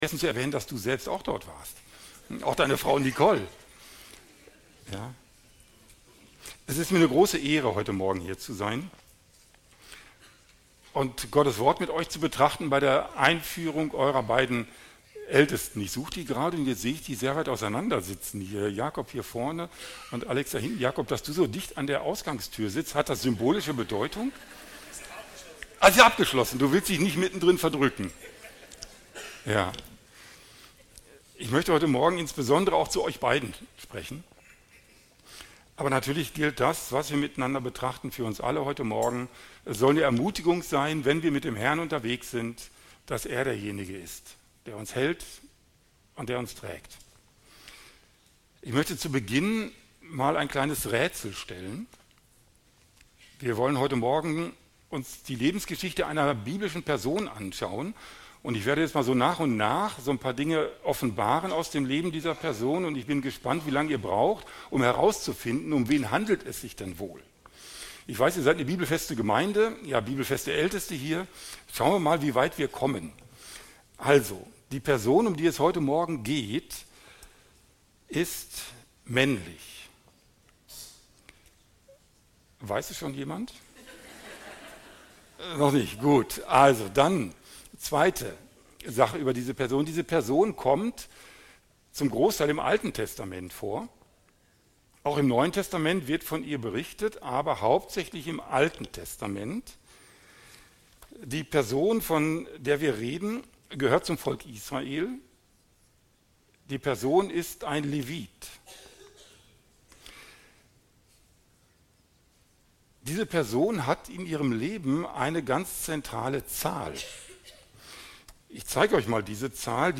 Predigten FCGL Mose Play Episode Pause Episode Mute/Unmute Episode Rewind 10 Seconds 1x Fast Forward 30 seconds 00:00 / 7:27 Datei herunterladen | Audiolänge: 7:27 | Aufgenommen am April 12, 2026 | 2.